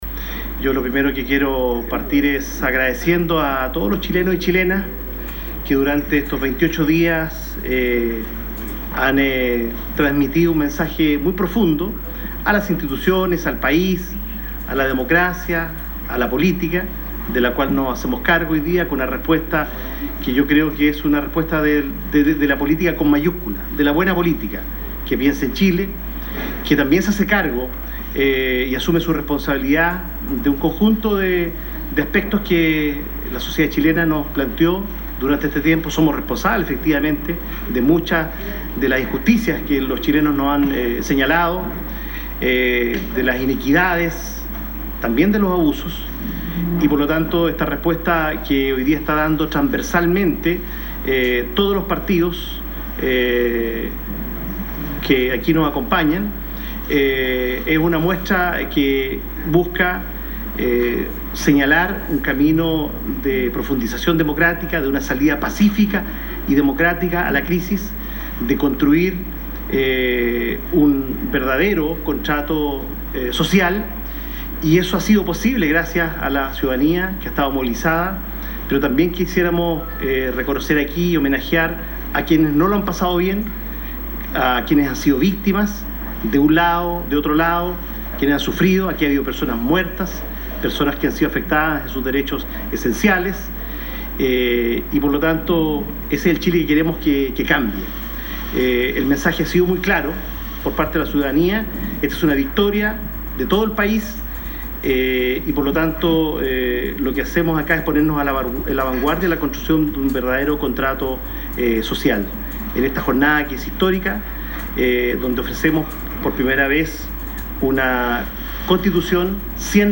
El texto del acuerdo alcanzado tras dos jornadas de diálogo, fue leído por el presidente del Senado, Jaime Quintana (PPD), quien lo calificó como «una victoria de todo el país, y lo que hacemos es ponernos a la vanguardia de la elaboración de un nuevo contrato social», indicó, reconociendo que el denominado «acuerdo por la paz y una nueva constitución» nació de la movilización pacífica de millones de chilenos, a partir del 18 de octubre pasado.